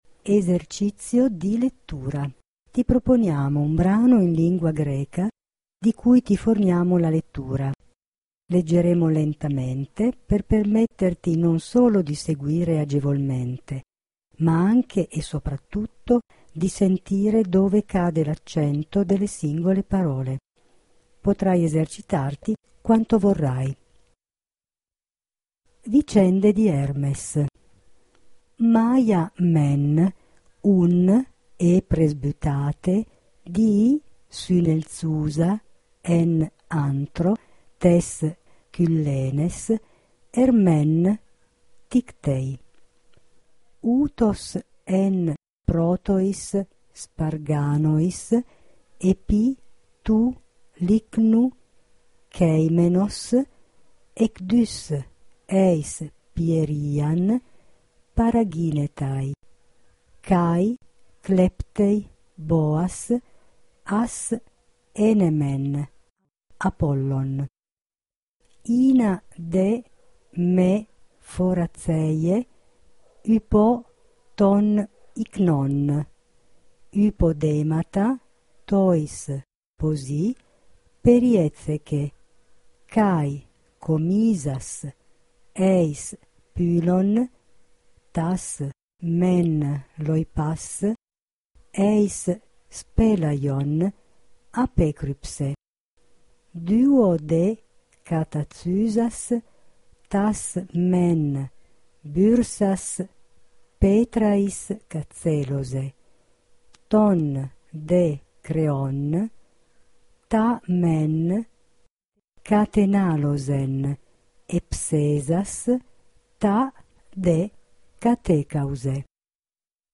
Vicende di Ermes.mp3) permette di sentire la lettura corretta del brano.
Esercizio di lettura.mp3